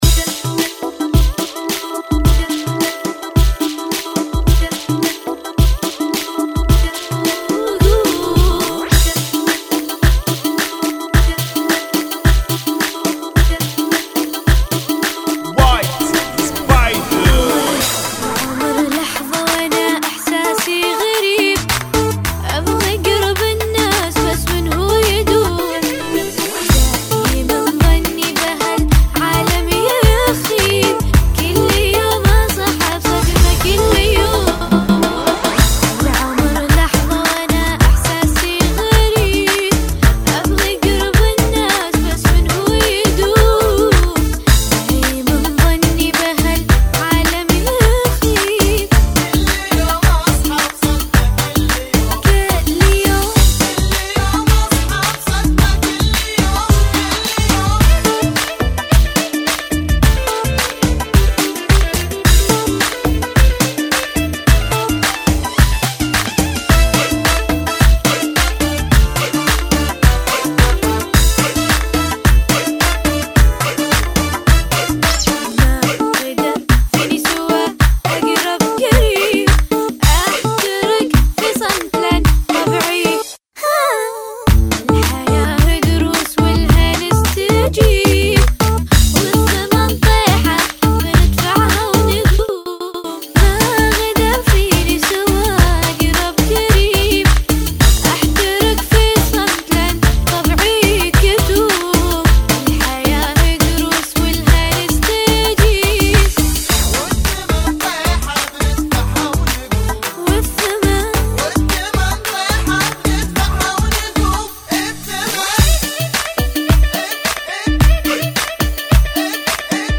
Funky [ 108 Bpm ]